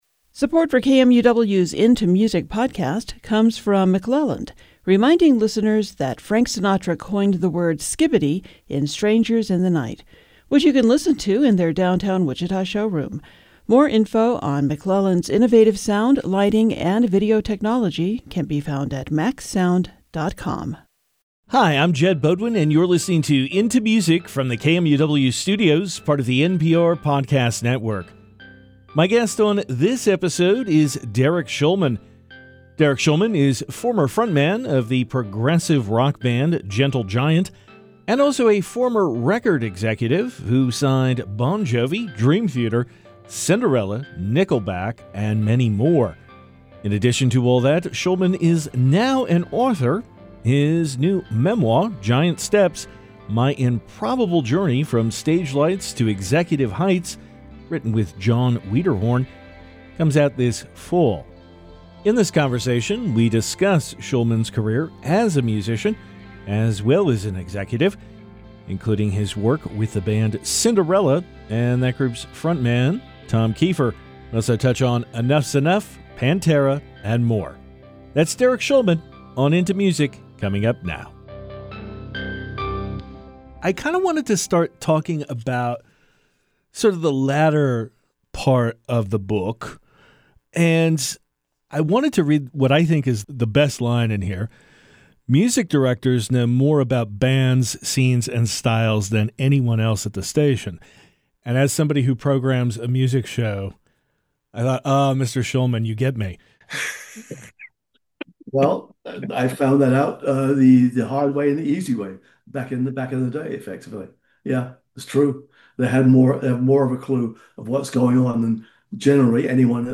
This episode was previously released on October 1, 2025 and is a conversation with author and musician Derek Shulman, known for his work with Gentle Giant and as a record executive who signed a number of multi-million-selling bands.